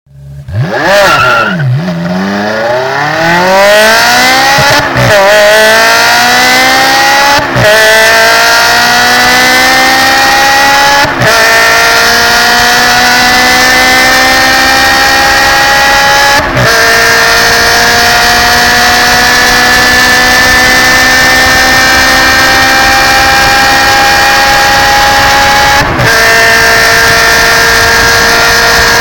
Ferrari gyorsulás
Ferrari_360_Modena.mp3